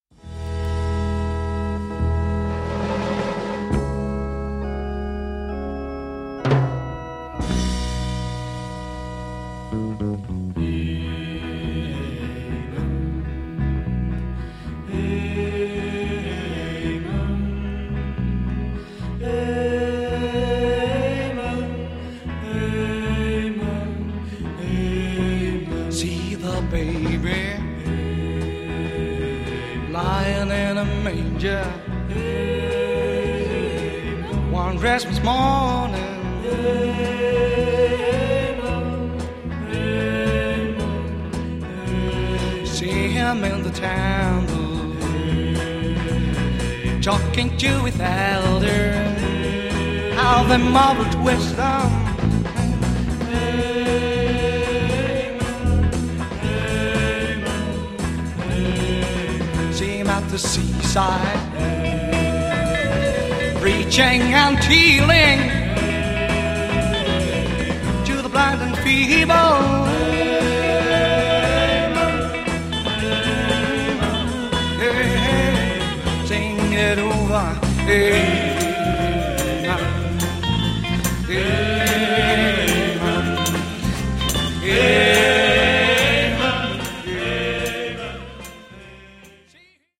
Zwei ausdrucksvolle englischsprachige Gospel-Songs
Gospel